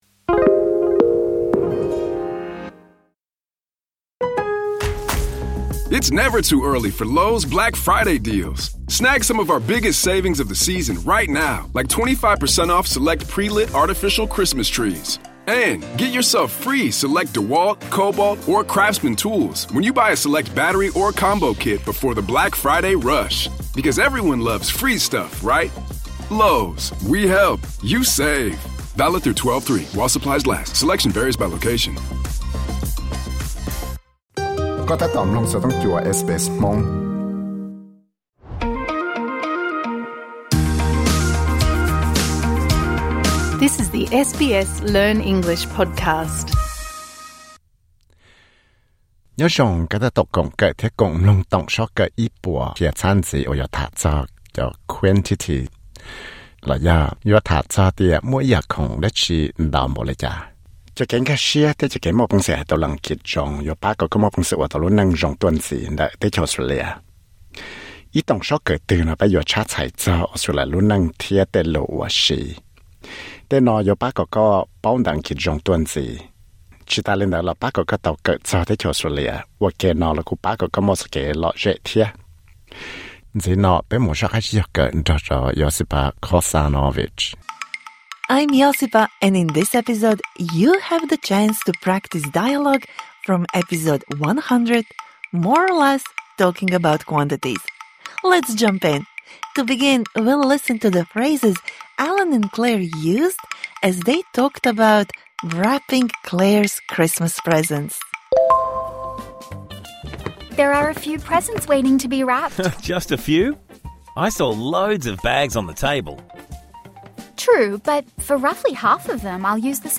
Mloog lwm yam xov xwm kaw ua suab tau ntawm no. Cov kev hais lus Askiv kom nrov nrov kiag yuav pab kom koj hais tau lus Askiv npliag thiab yuav pab kom kom nco tau cov lo lus yooj yim zog tuaj ntxiv.